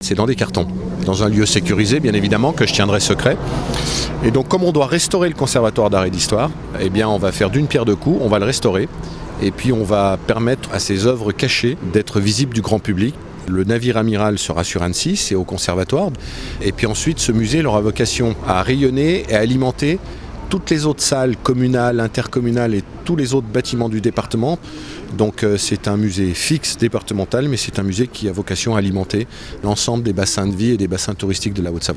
Et ce, sur tout le territoire comme l’explique Martial Saddier, le président du Département de la Haute-Savoie.